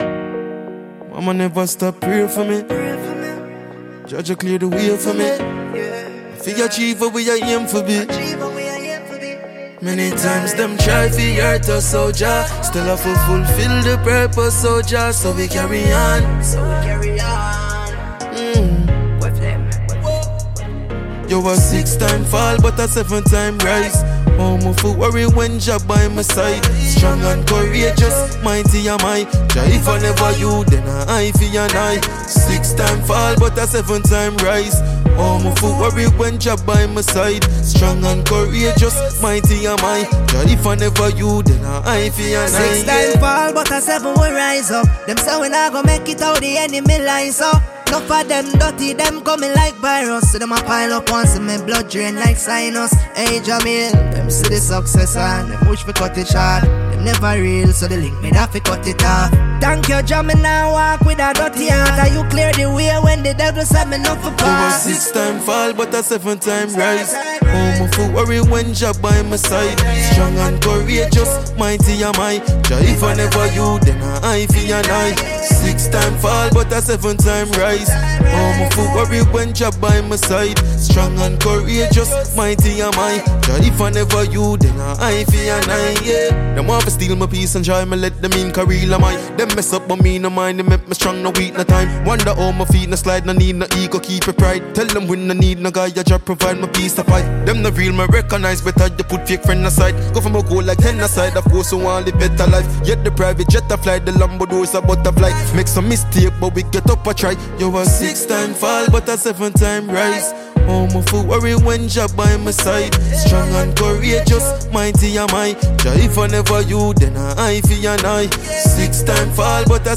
Jamaican dancehall musician